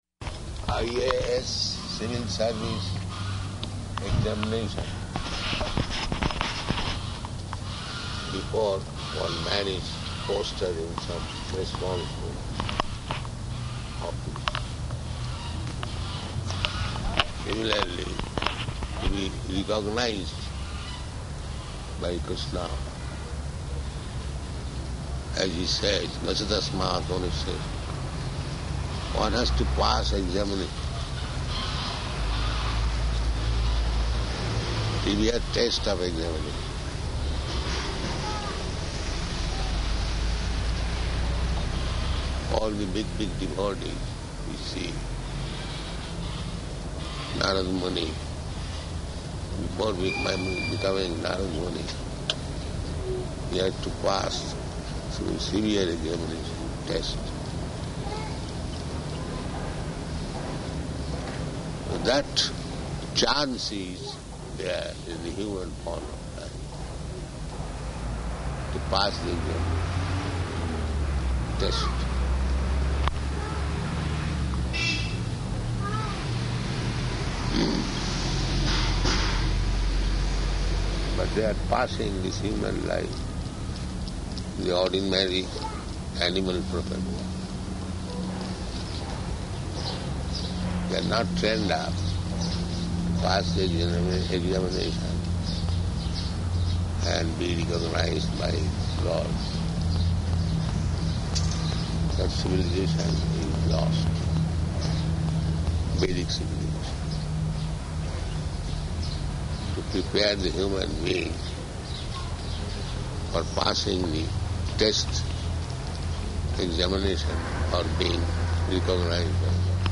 Morning Walk --:-- --:-- Type: Walk Dated: August 11th 1976 Location: Tehran Audio file: 760811MW.TEH.mp3 Prabhupāda: ...I.A.S. civil service examination before one man is posted in some responsible office.